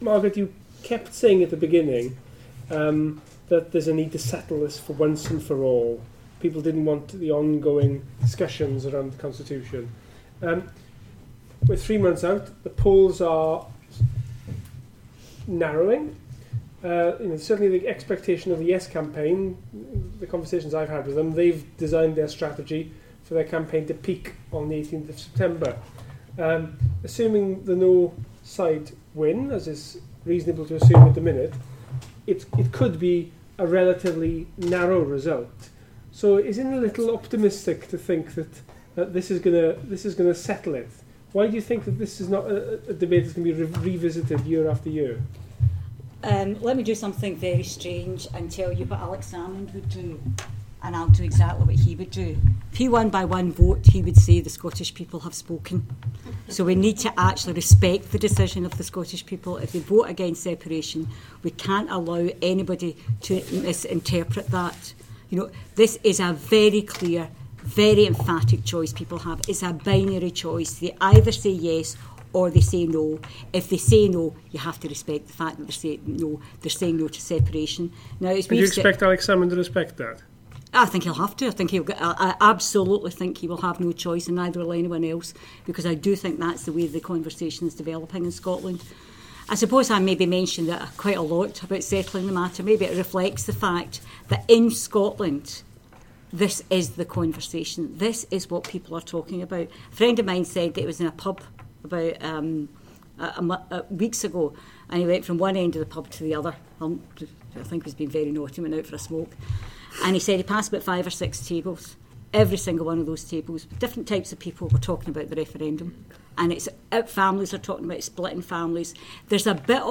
The discussion which followed speeches by Labour's Shadow Secretaries of State for Wales and Scotland, Owen Smith and Margaret Curran. IWA Director Lee Waters chairs a Q&A session with IWA members at an event in the National Assembly for Wales on 19th June 2014